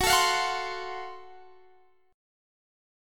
Listen to GbmM7b5 strummed